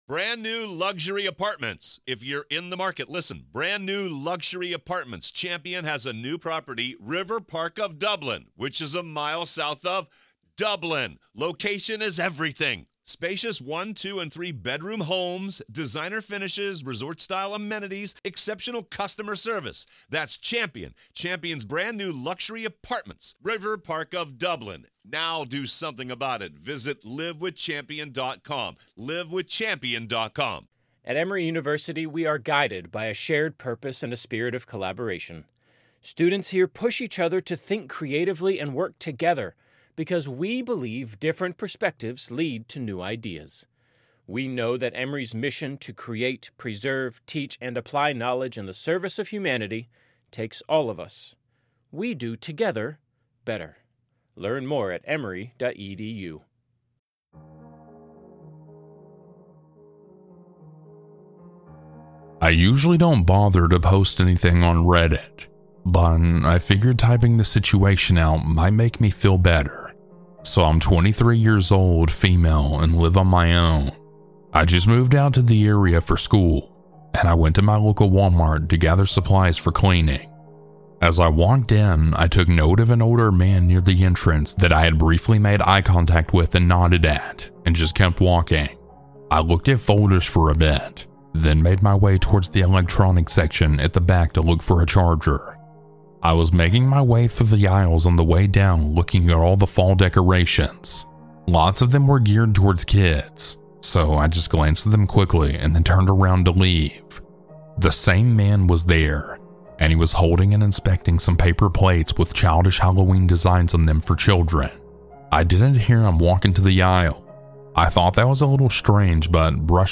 - Anonymous Huge Thanks to these talented folks for their creepy music!